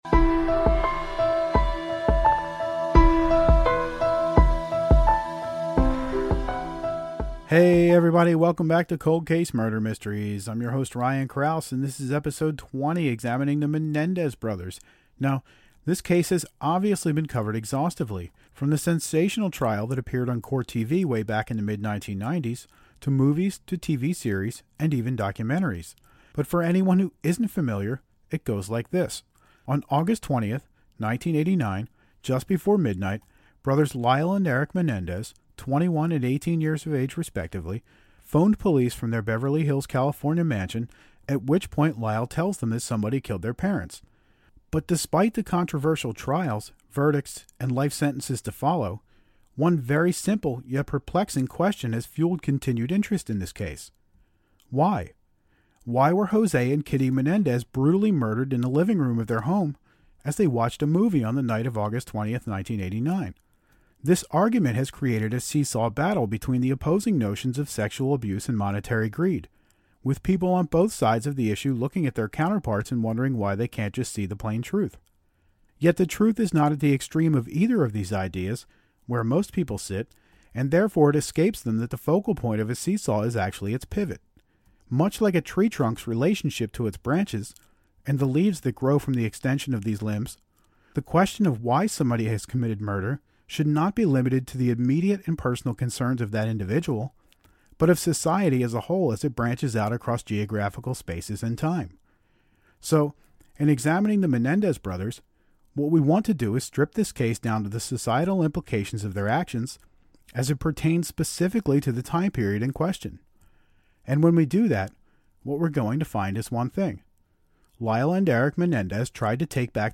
True Crime